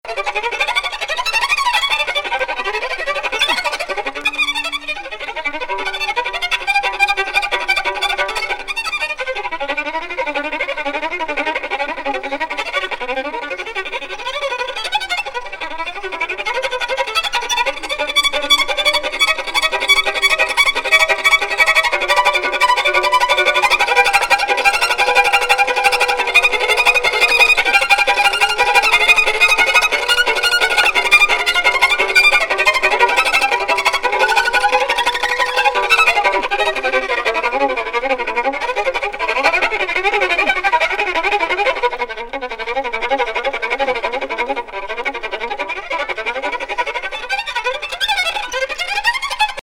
アンコールで演奏される黒人霊歌B面終盤。